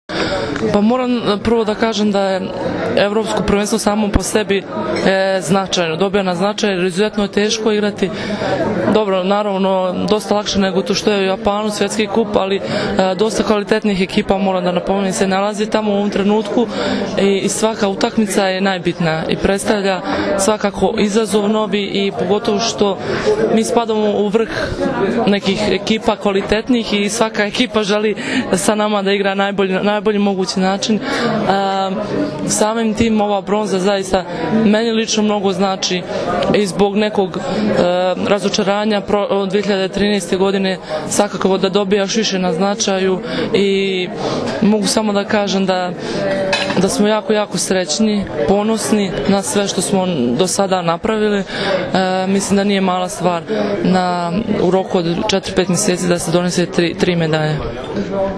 Odbojkašice su zatim produžile u beogradski hotel „M“, gde im je priređen svečani doček.
IZJAVA BRANKICE MIHAJLOVIĆ